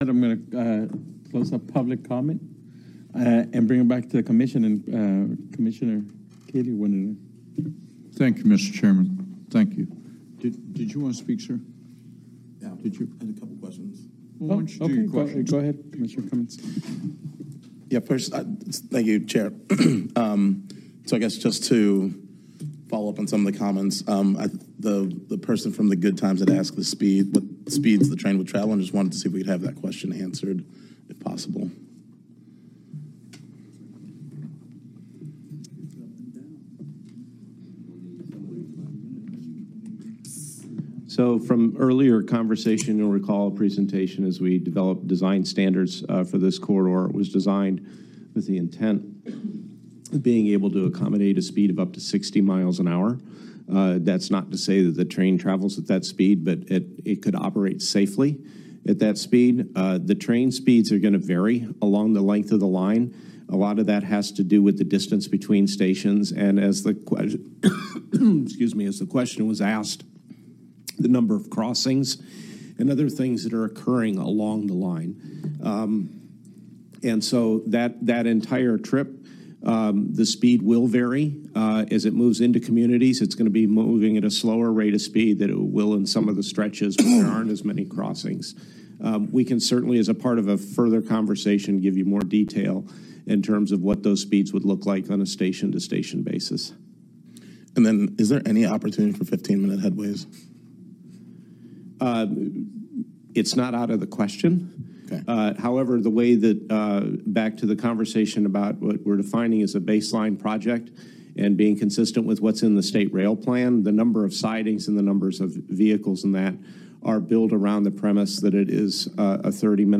ZEPRT Update - Commissioner comments continued